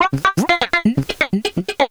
Index of /90_sSampleCDs/Houseworx/12 Vocals/74 Processed Vocal Loops